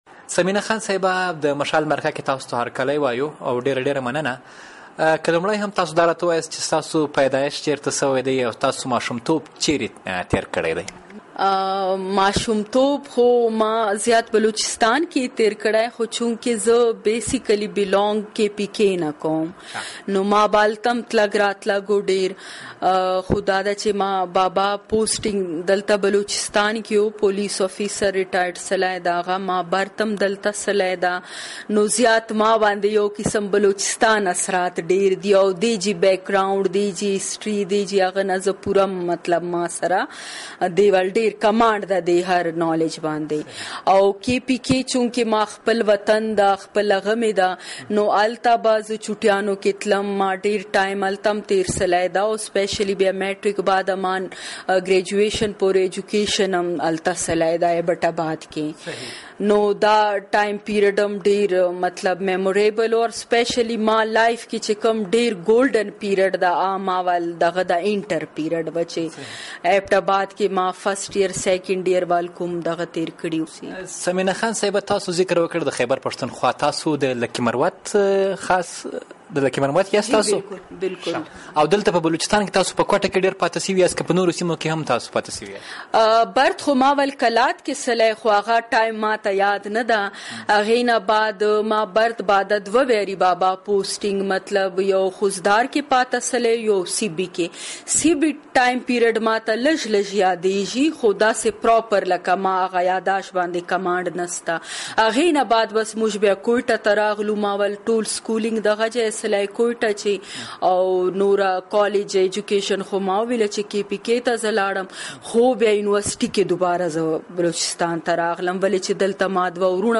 د مشال ددې اونۍ مرکه په کویټه کې د بلوچستان د صوبايي اسمبلۍ د ښځینه غړې ثمینه خان سره ده.